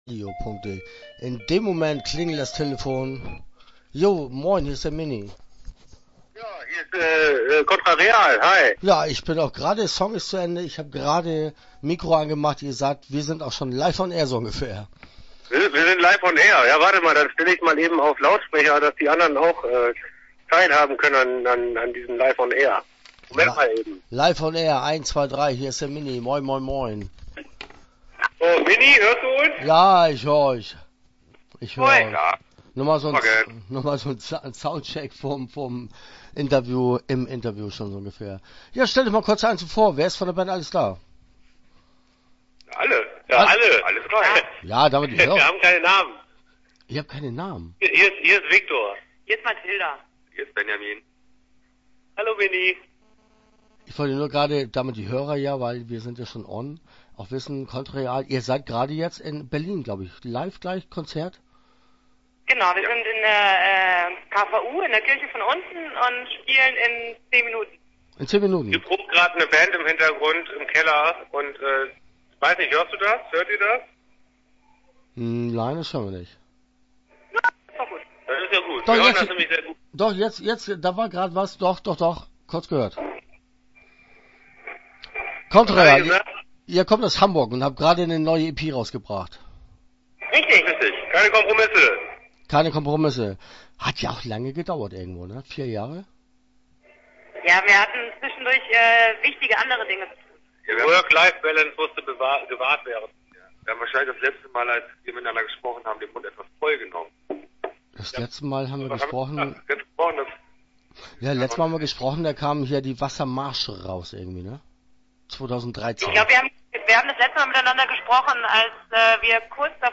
Interview Teil 1 (10:27)